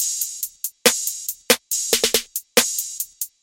Trap Beat Drum Top Loop no 808
Tag: 140 bpm Trap Loops Drum Loops 590.80 KB wav Key : Unknown